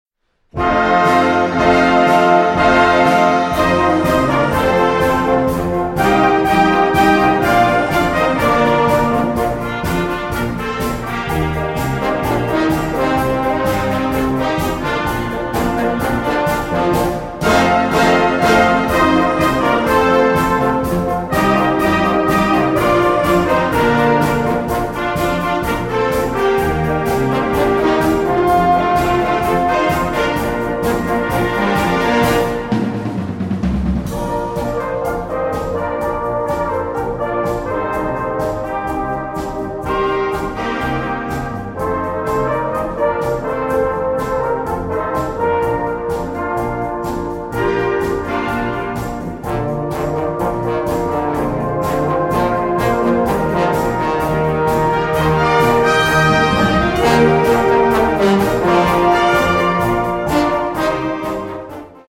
Een medley